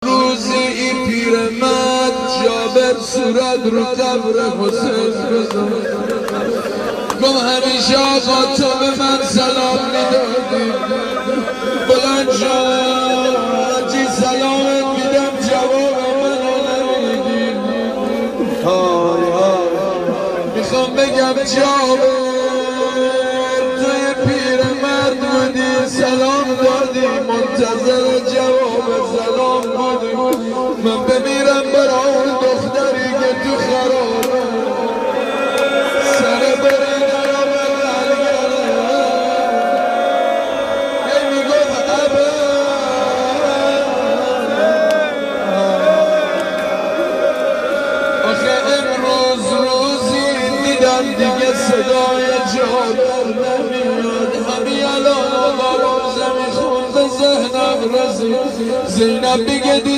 روضه حضرت رقیه(س)